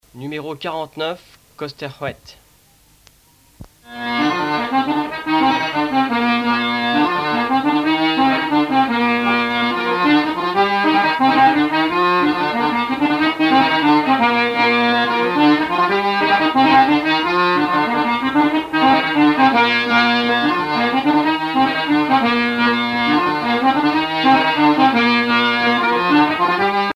danse : kost ar c'hoad
Pièce musicale éditée